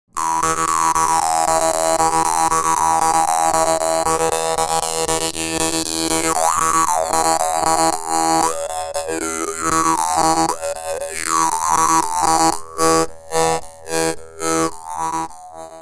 VIETNAMESE JAW HARP · DAN MOI
Hand-made by a small number of excellent local craftsmen, this brass instrument captivates even novices by being easily playable and having a brilliant sound, rich in overtones.
This ensures a wide variety of sounds, especially in the high ranges."